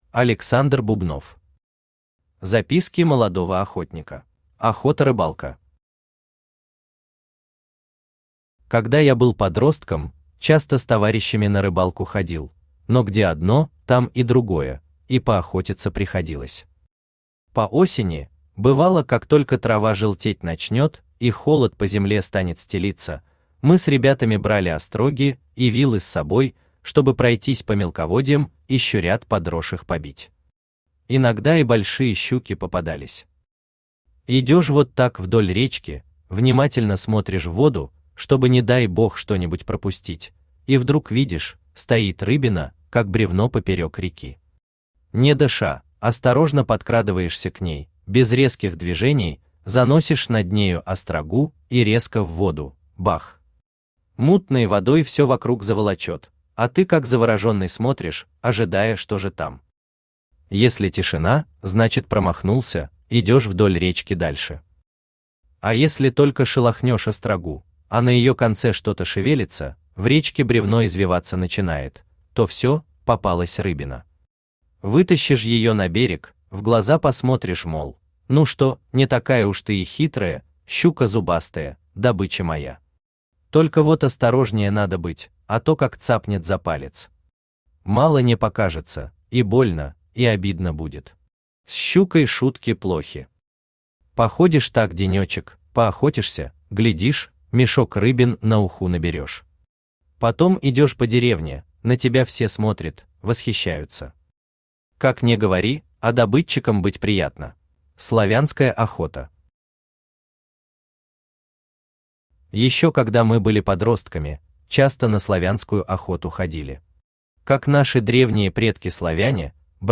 Аудиокнига Записки молодого охотника | Библиотека аудиокниг
Aудиокнига Записки молодого охотника Автор Александр Иванович Бубнов Читает аудиокнигу Авточтец ЛитРес.